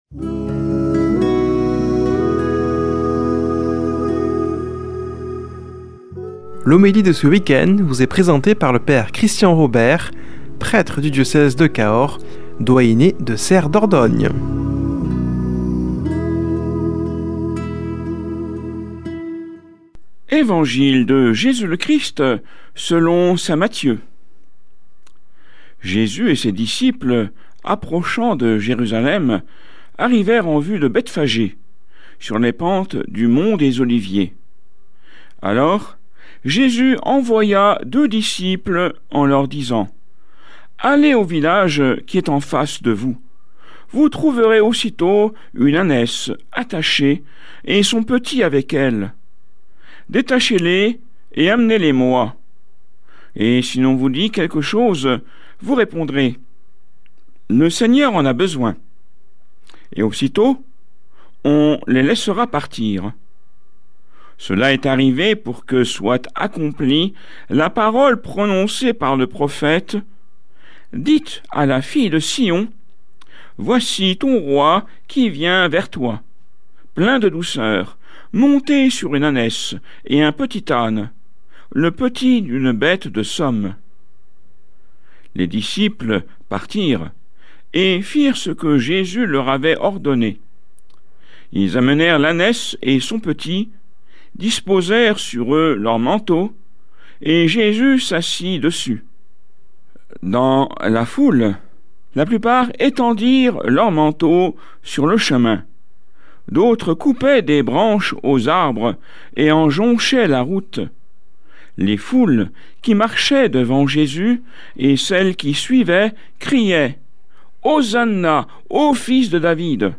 Homélie du 28 mars